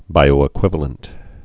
(bīō-ĭ-kwĭvə-lənt)